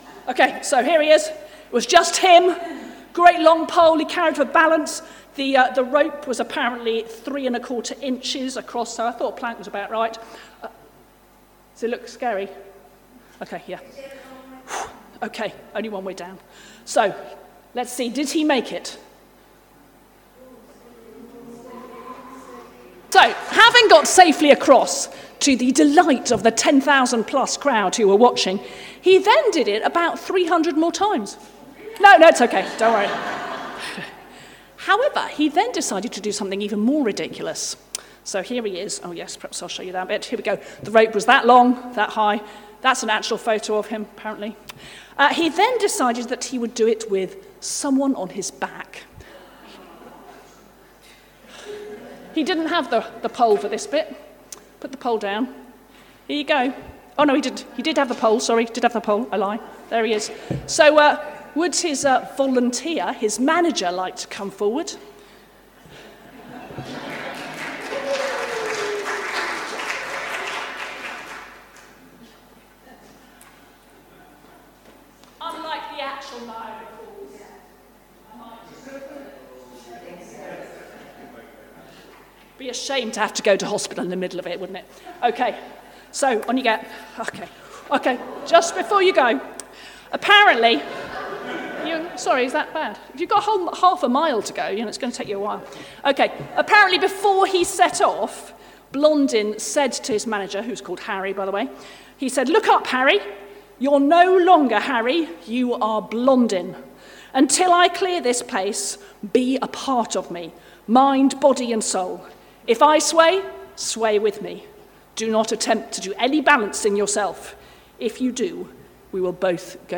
Talks, 9 February 2025